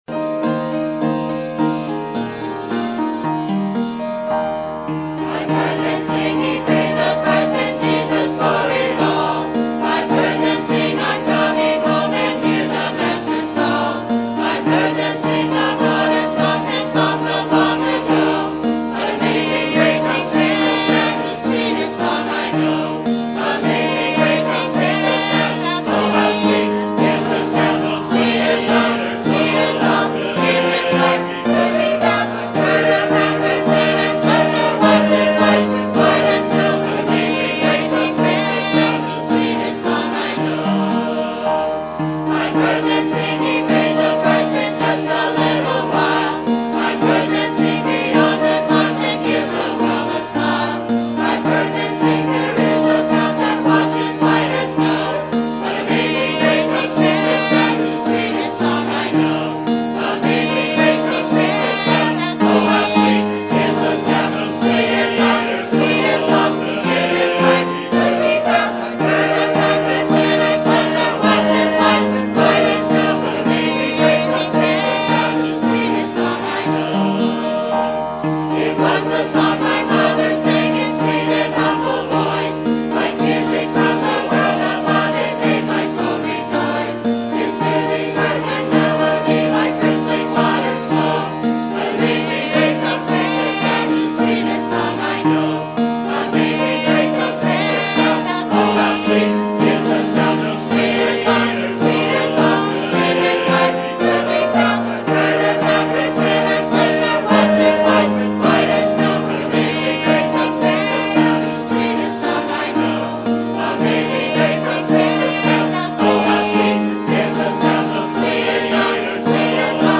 Sermon Topic: Winter Revival 2015 Sermon Type: Special Sermon Audio: Sermon download: Download (19.12 MB) Sermon Tags: Isaiah Revival Iniquities Sins